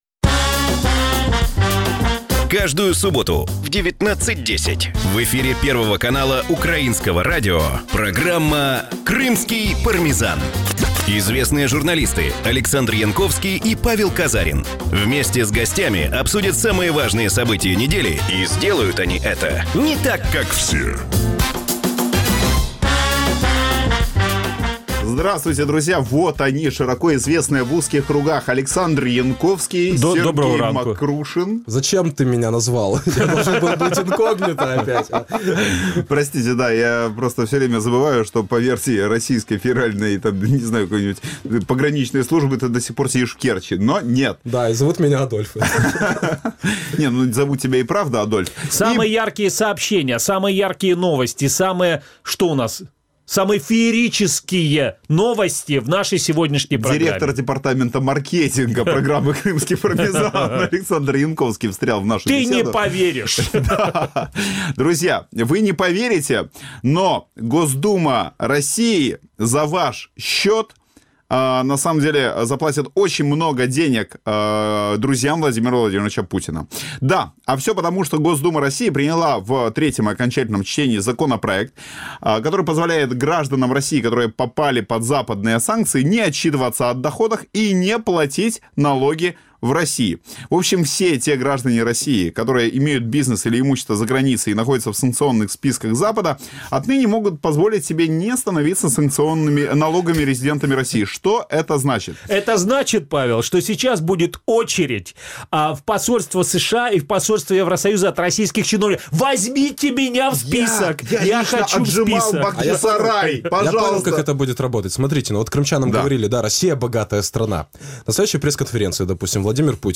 Програма звучить в ефірі Радіо Крим.Реалії. Час ефіру: 18:10 – 18:40.